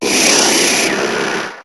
Cri d'Alakazam dans Pokémon HOME.